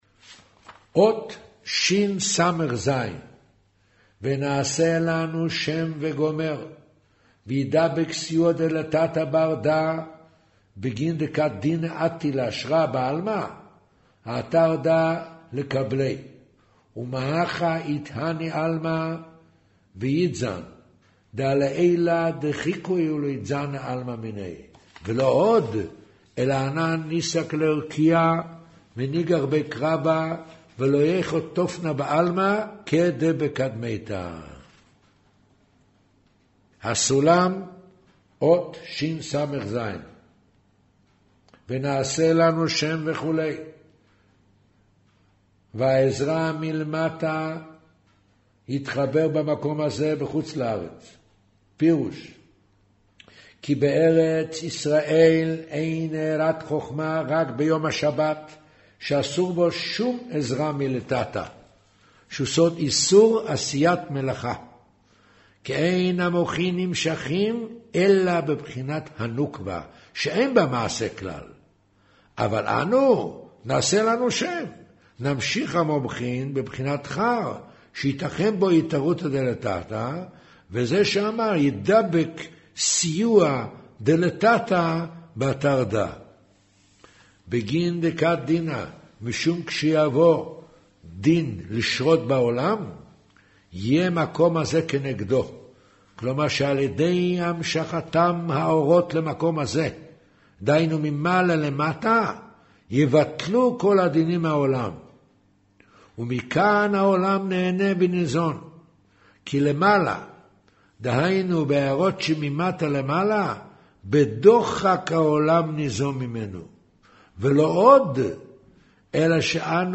אודיו - קריינות זהר, פרשת נח, מאמר שער החצר הפנימית